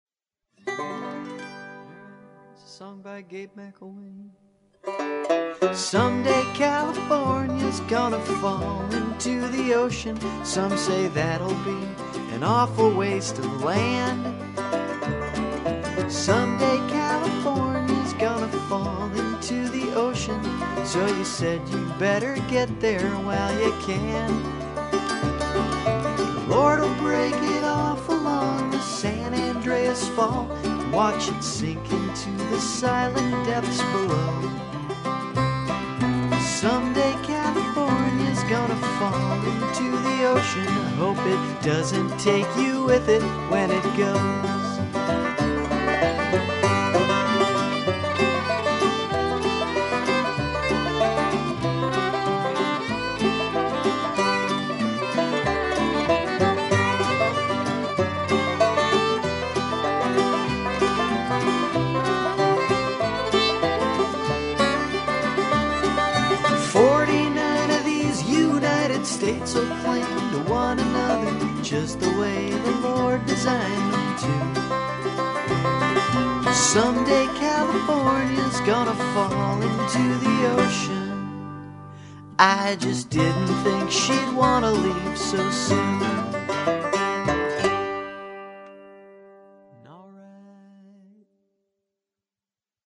The fiddle solo